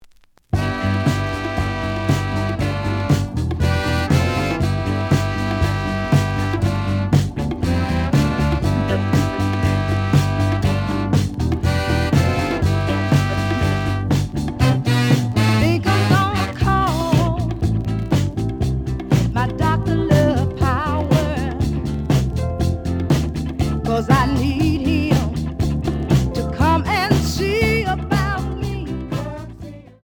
The audio sample is recorded from the actual item.
●Genre: Soul, 70's Soul
A side plays good.